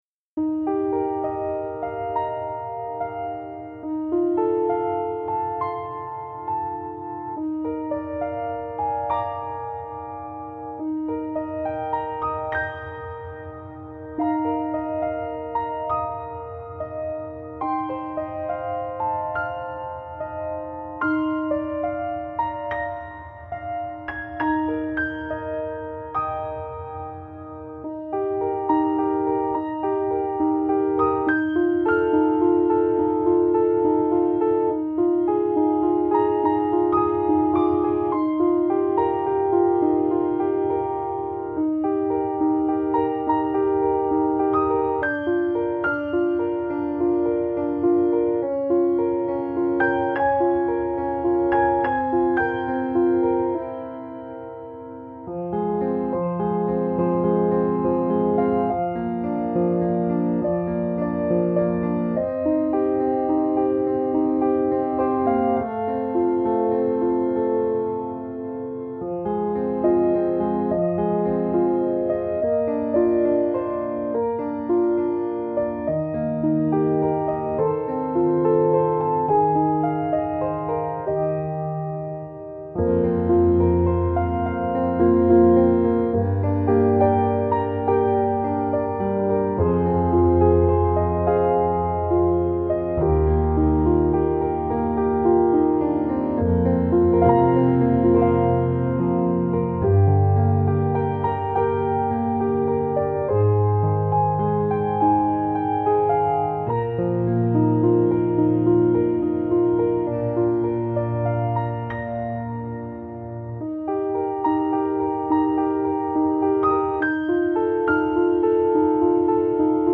Laughter and Tears – Piano Melody